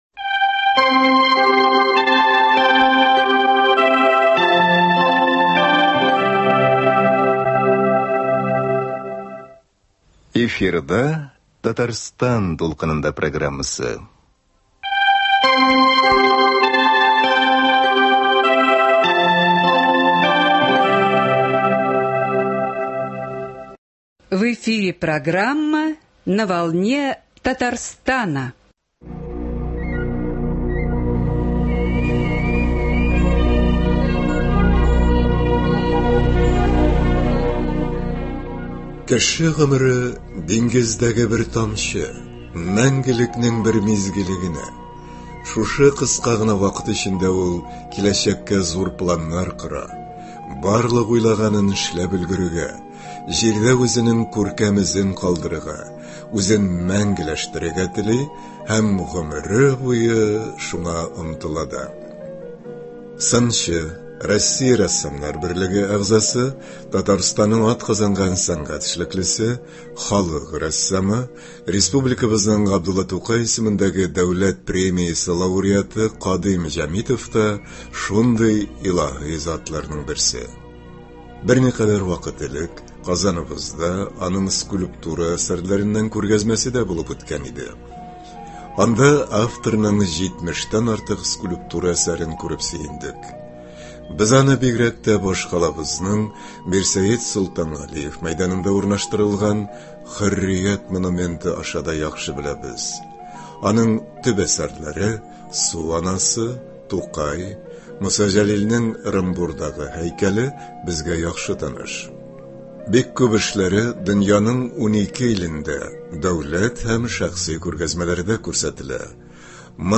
әңгәмә тәкъдим итәбез.